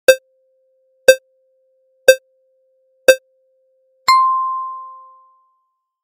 SE（カウントダウン5秒）